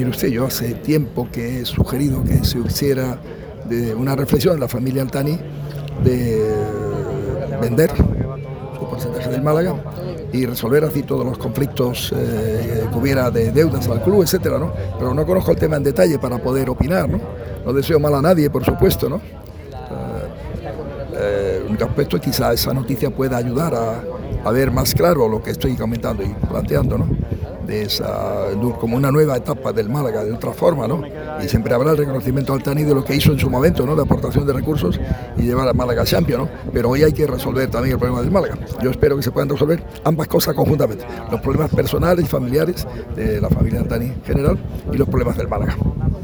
El alcalde de Málaga opina abiertamente sobre la petición de la Fiscalía de 14 años de prisión para el catarí.
Ha sido en Kaleido Málaga Port, situado en el Palmeral de las Sorpresas, sede de la presentación oficial de la VII Málaga Sailing Cup.